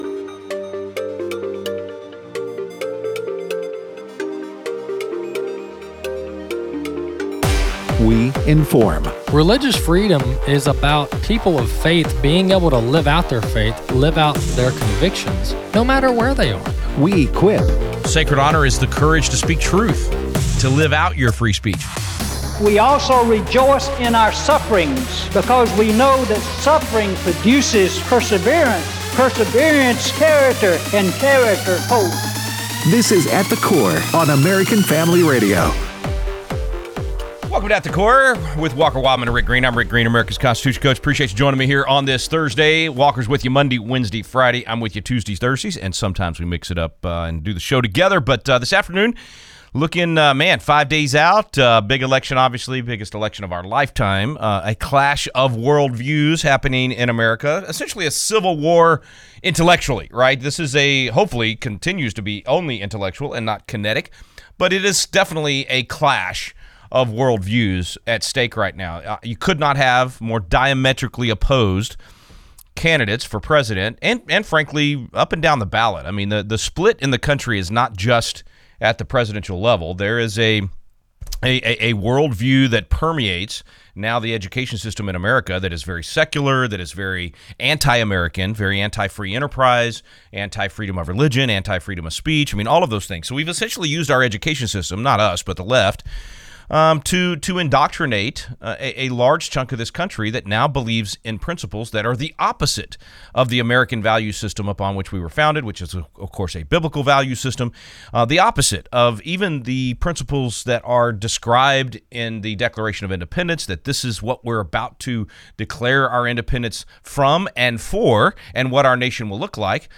Show Notes 0:00 - 15:00: To vote or not to vote… 18:00 - 34:10: Who would you make the bus driver? Callers provide their thoughts 37:00 - 54:00: Folks can feel the energy!